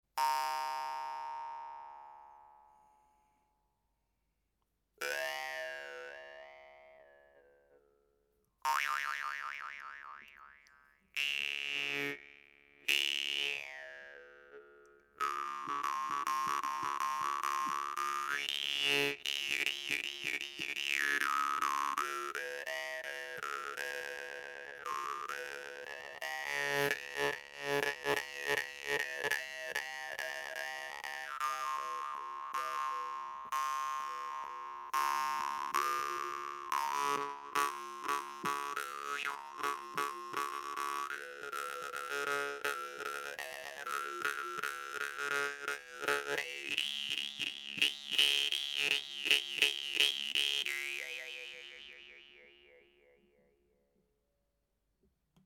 Douce, fine et assez souple, la Khomus TARTAKOV est un excellent instrument facile à faire sonner, sans avoir à se battre! Cette guimbarde est très sensible au souffle et permet de jouer mélodiquement, elle est néanmoins puissante.